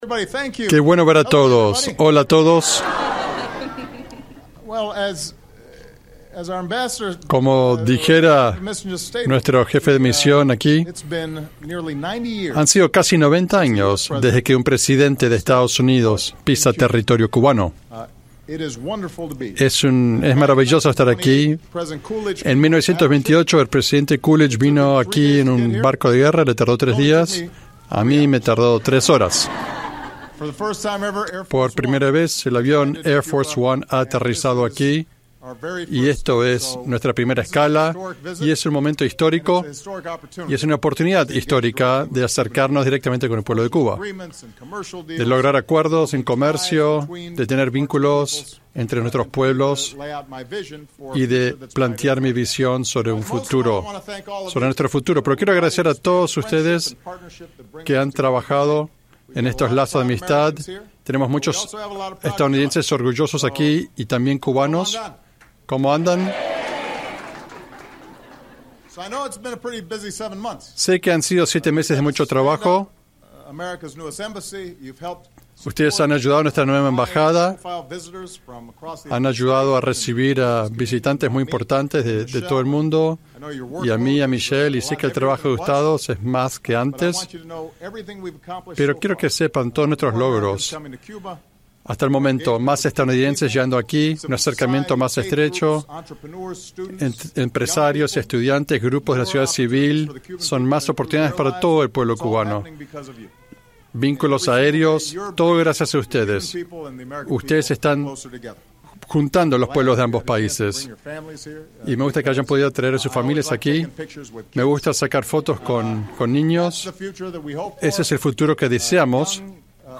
Discurso de Obama en embajada estadounidense en La Habana